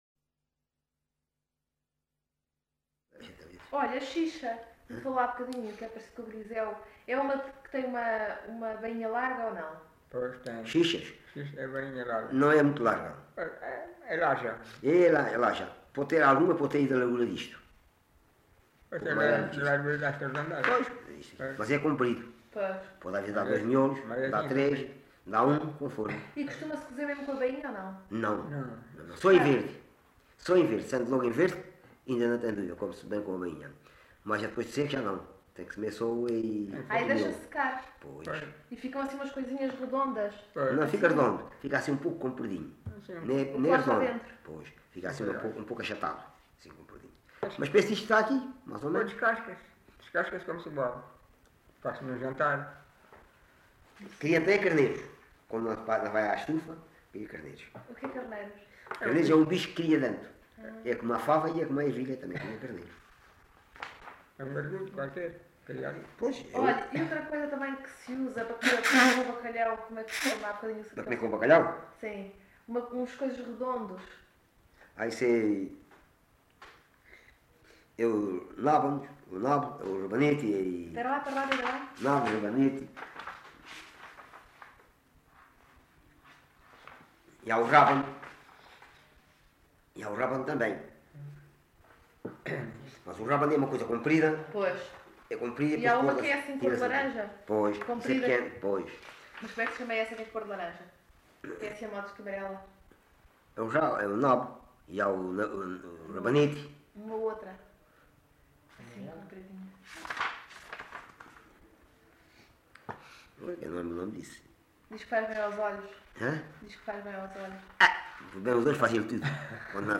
LocalidadeAlte (Loulé, Faro)